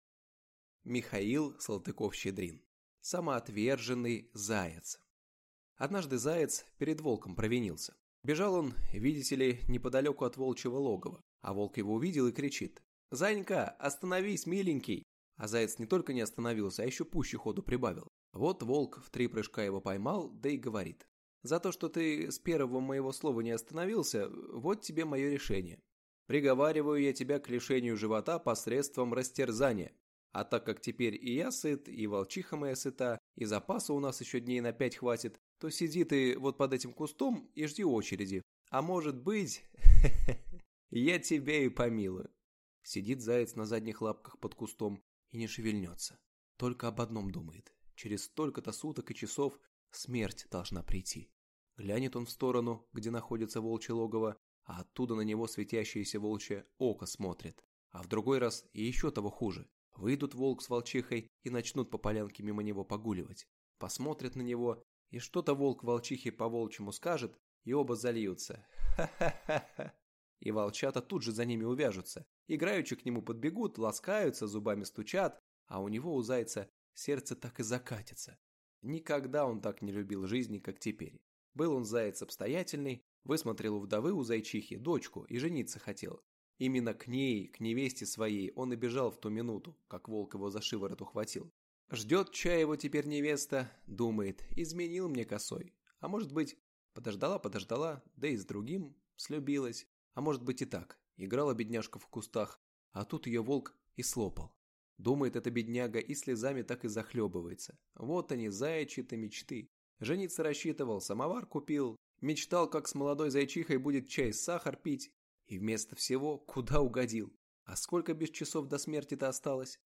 Аудиокнига Самоотверженный заяц | Библиотека аудиокниг